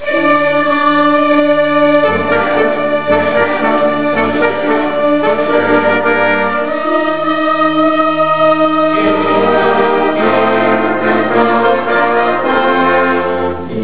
South Whidbey High School
The Finale of the concert was Handel's "Hallelujah Chorus"